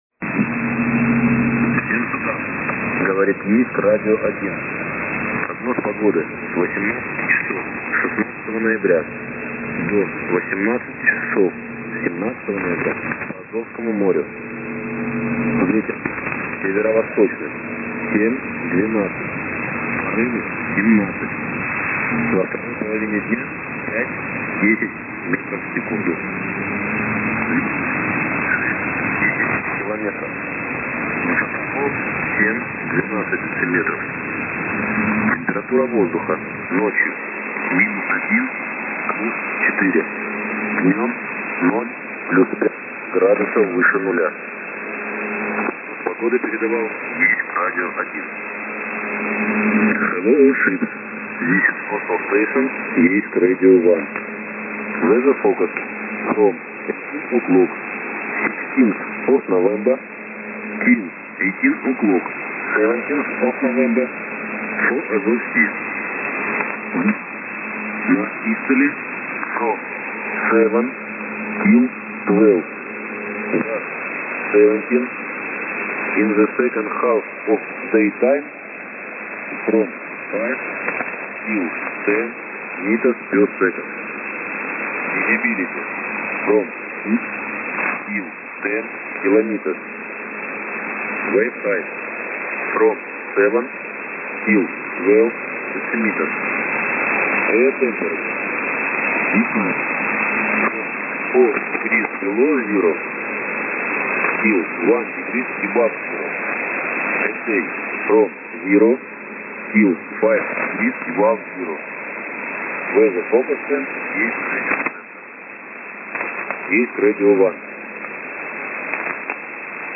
Начало » Записи » Записи радиопереговоров - корабли и береговые станции
На частоте 2,620 Мгц принято штормовое предупреждение 16.11.2016 в 18:10 МСК.